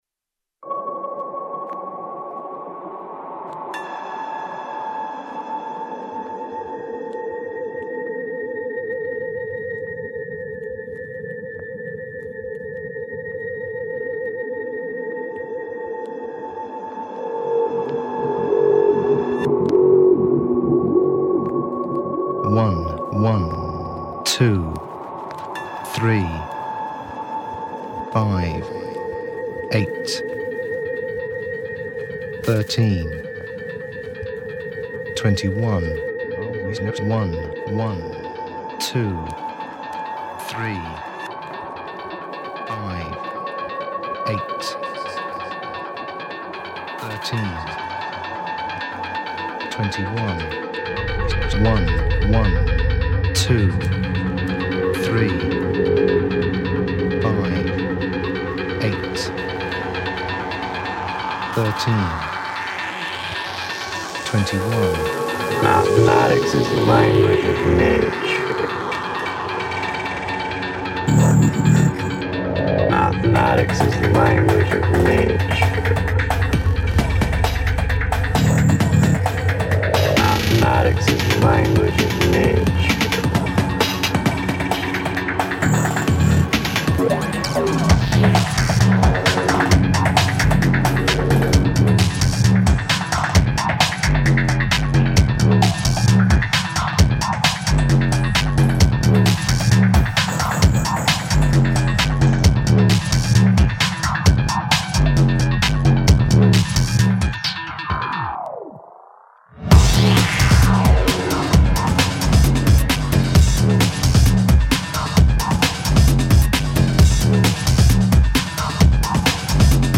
Recorded: April 2003 | Genre: Breakbeat